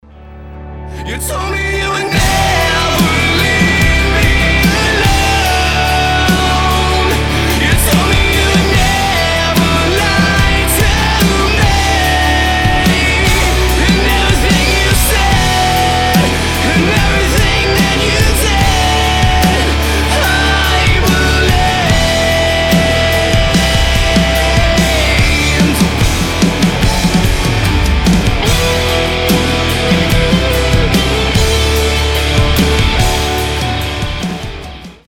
• Качество: 320, Stereo
мужской вокал
громкие
грустные
Alternative Rock
Hard rock